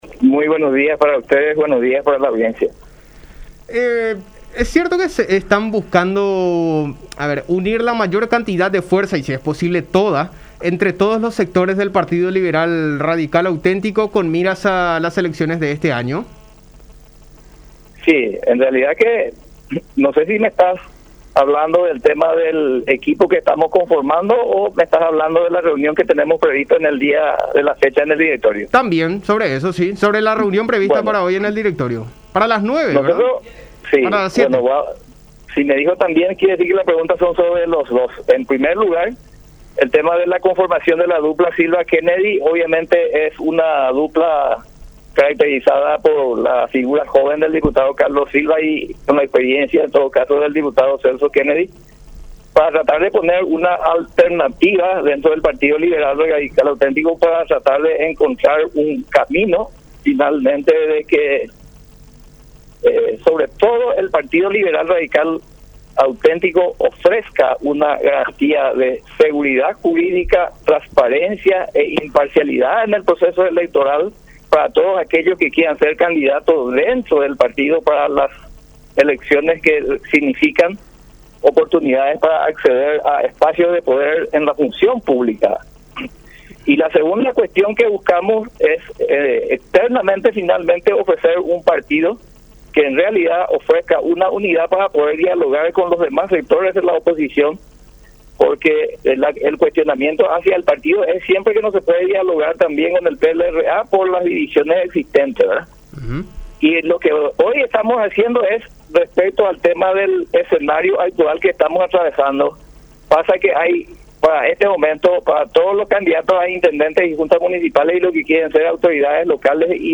“Queremos encontrar un camino que ofrezca una garantía de imparcialidad en el proceso electoral, para quienes quieran ser candidatos mirando las elecciones. Muchas veces se genera mucha incertidumbre y puede ser que dialogando se tenga un sistema electoral único para conformar un TEI (Tribunal Electoral Independiente) único e imparcial”, afirmó Kennedy en conversación con La Unión.